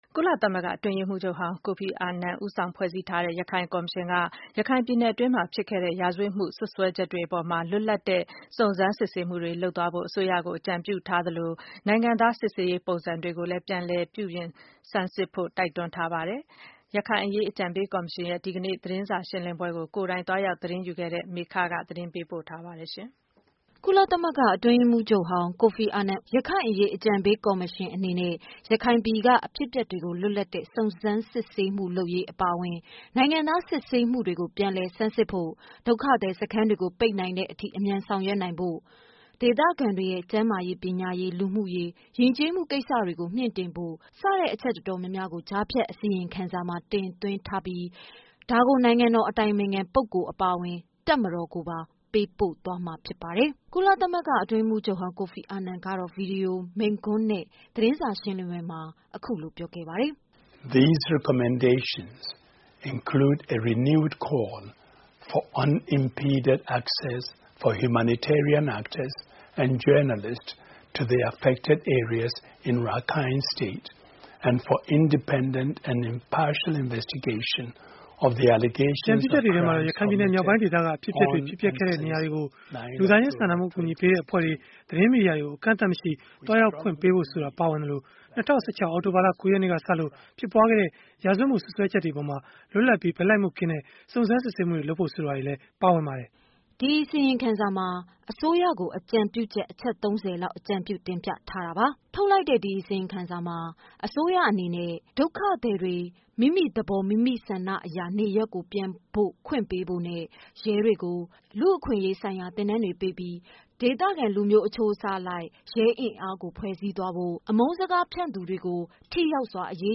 ကုလအတွင်းရေးမှုးချုပ်ဟောင်း ကိုဖီအာနန်ဦးဆောင်တဲ့ ရခိုင်အရေး အကြံပေးကော်မရှင်အနေနဲ့ ရခိုင်ပြည်က အဖြစ်အပျက်တွေကို လွတ်လပ်တဲ့ စုံစမ်းမှုလုပ်ရေးအပါအဝင် နိုင်ငံသားစီစစ်မှုတွေကို ပြန်လည်းဆန်းစစ်ဖို့၊ ဒုက္ခသည်စခန်းတွေကို ပိတ်နိုင်တဲ့အထိ အမြန်ဆောင်ရွက်သွားဖို့ ၊ ဒေသခံတွေရဲ့ကျန်းမာရေး ပညာရေး လူမှုရေး ယဉ်ကျေးမှုကိစ္စရပ်တွေကို မြင့်တင်ဖို့ စတဲ့အချက်အတော်များများကို ကြားဖြတ်အစီရင်ခံစာမှာ ထည့်သွင်းထားပြီး ဒါကို နိုင်ငံတော်အတိုင်ပင်ခံပုဂ္ဂိုလ်ကို ပေးပို့သွားမှာဖြစ်ပါတယ်။ ကုလသမဂ္ဂ အတွင်းရေးမှုးချုပ်ဟောင်း ကိုဖီအာနန်ကတော့ ဗွီဒီယိုမိန့်ခွန်းနဲ့ သတင်းစာရှင်းလင်းပွဲမှာအခုလို ပြောခဲ့ပါတယ်။